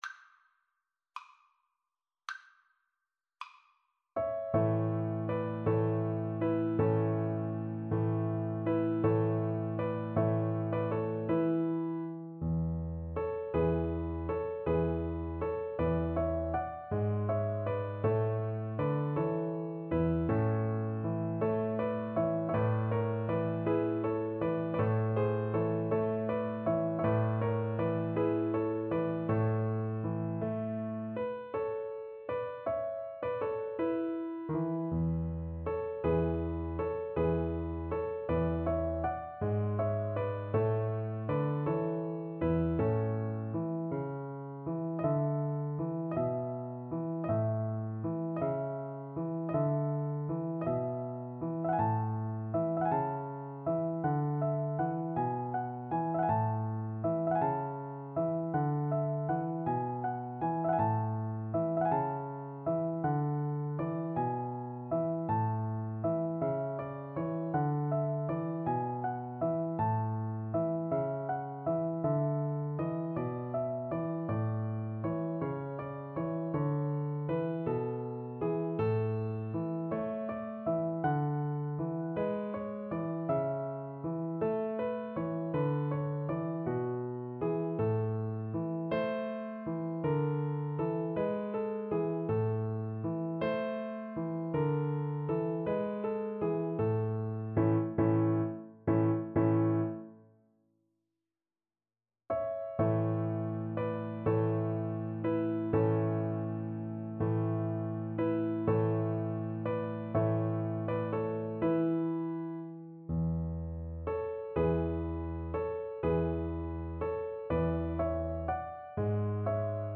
Play (or use space bar on your keyboard) Pause Music Playalong - Piano Accompaniment Playalong Band Accompaniment not yet available transpose reset tempo print settings full screen
Sicilian carol
A major (Sounding Pitch) (View more A major Music for Voice )
6/8 (View more 6/8 Music)
~ = 80 Allegro moderato (View more music marked Allegro)